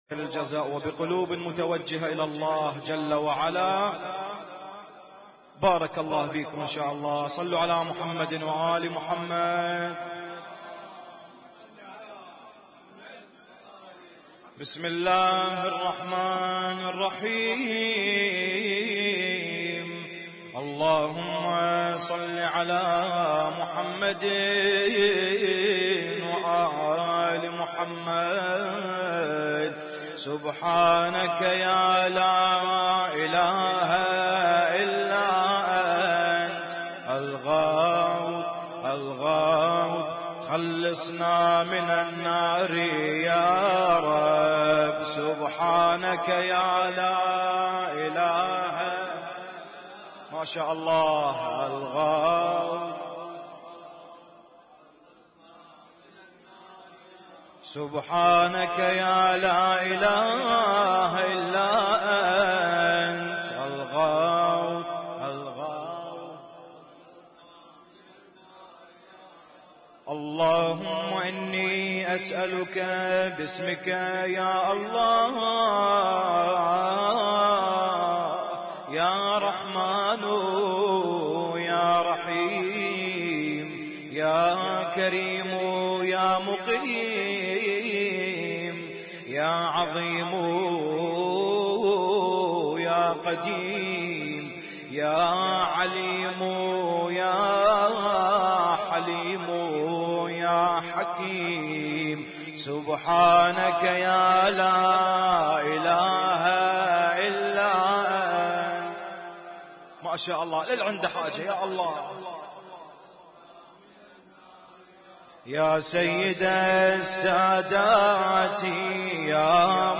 اسم التصنيف: المـكتبة الصــوتيه >> الادعية >> ادعية ليالي القدر